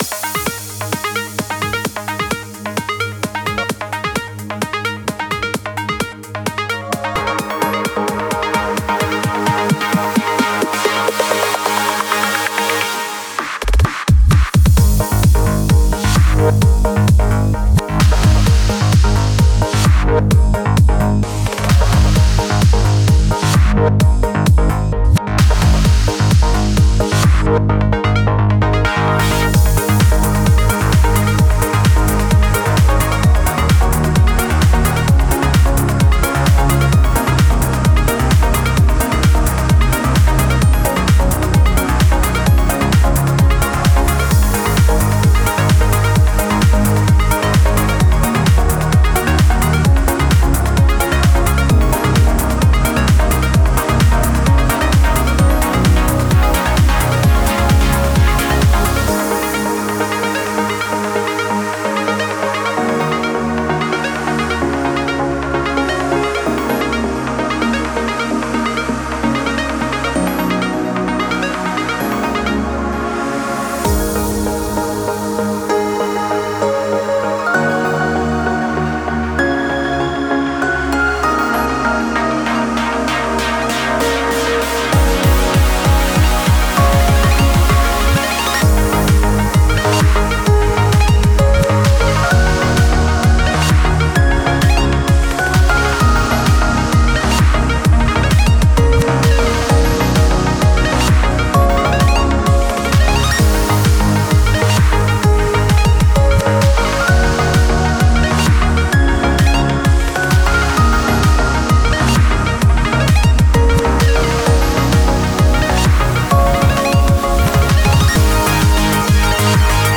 BPM130
Audio QualityPerfect (High Quality)
Comments[Progressive Trance]